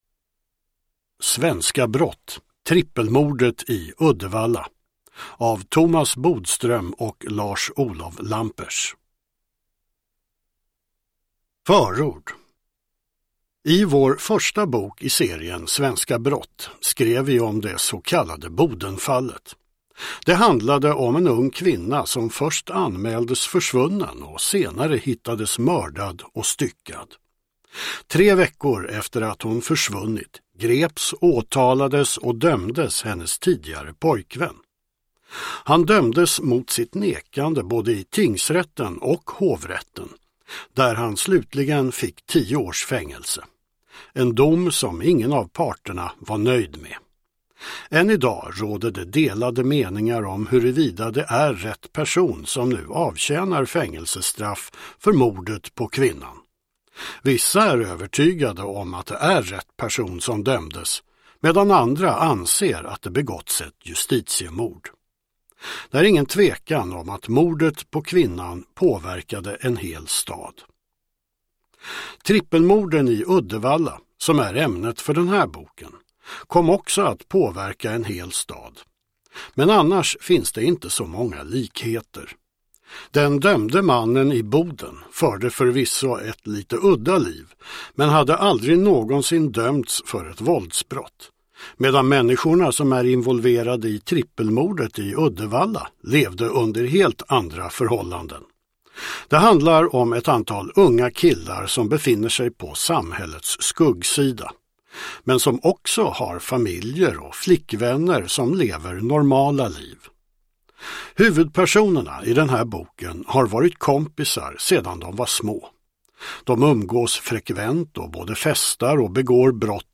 Trippelmordet i Uddevalla – Ljudbok – Laddas ner